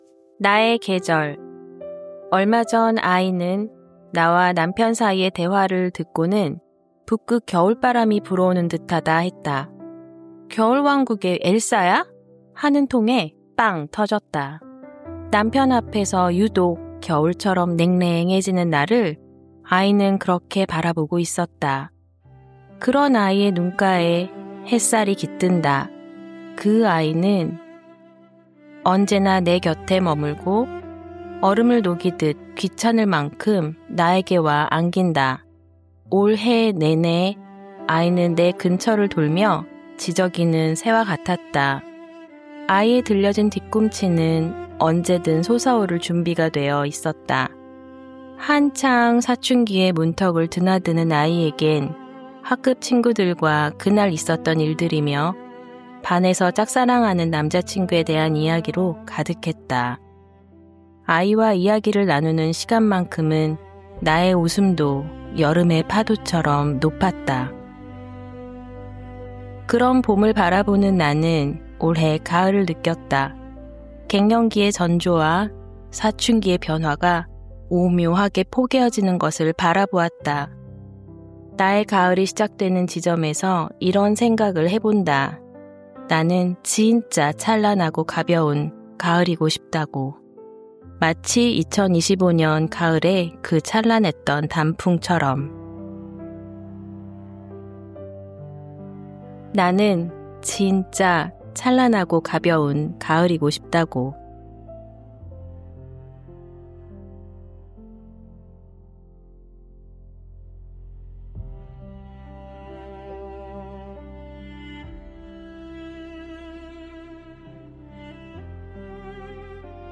어설프고 서투른 낭독.
내 목소리로 삐뚤 빼뚤 낭독한 내 글을, 기록으로 이 곳에 올려 둔다.